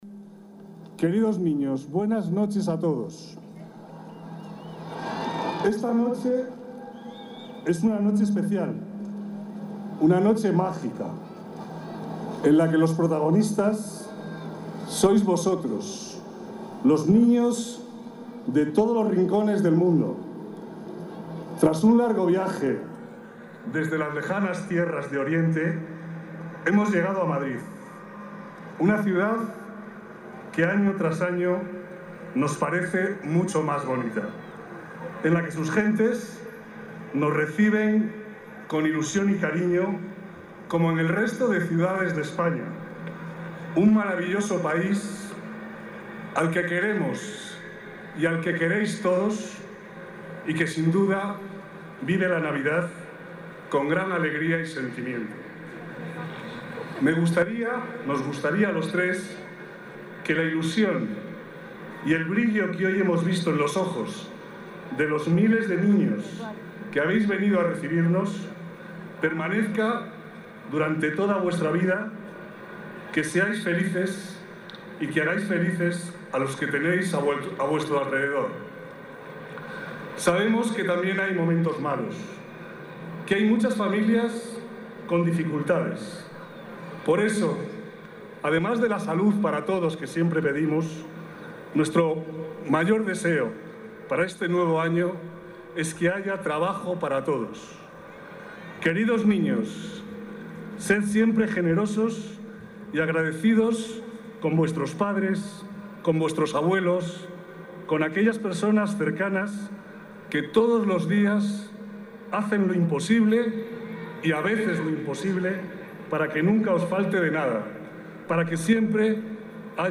Nueva ventana:Discurso del Rey Melchor